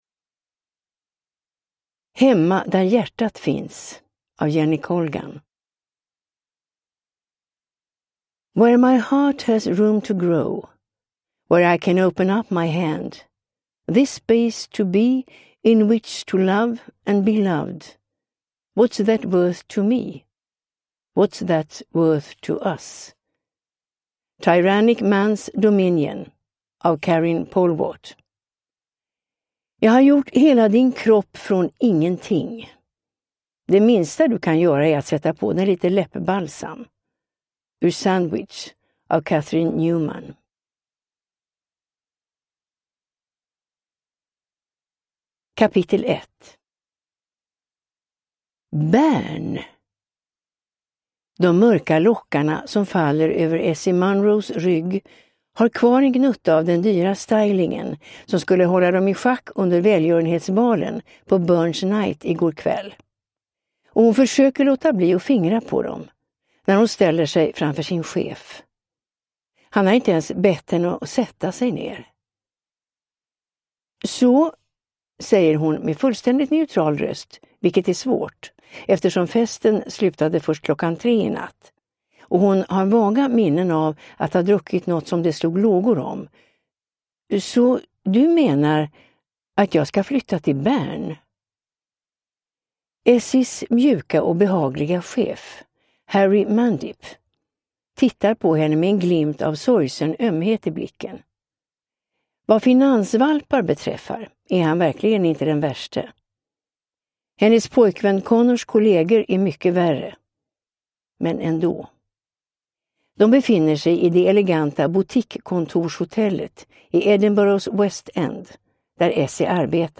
Hemma där hjärtat finns – Ljudbok